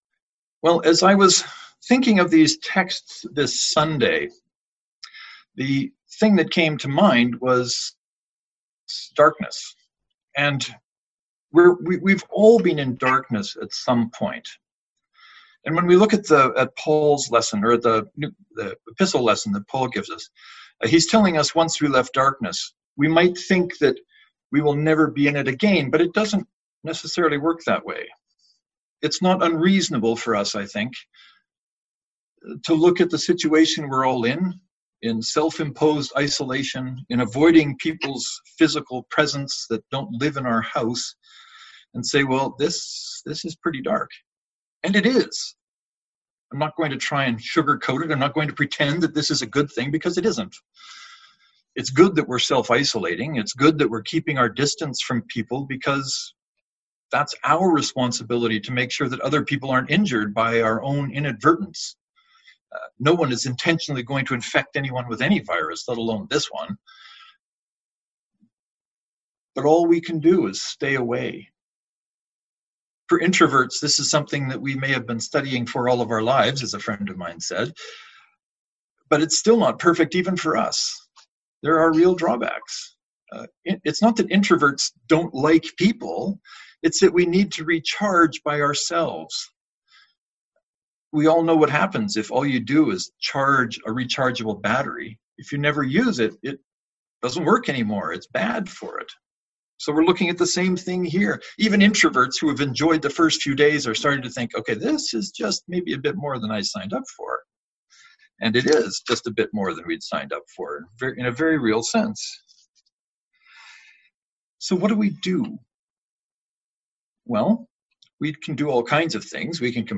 Today’s church service happened over an online service called Zoom and it was, if not perfect by any means, a meaningful church service.
“When we are in darkness” joint online sermon (to download, right-click and select “Save Link As . . .”)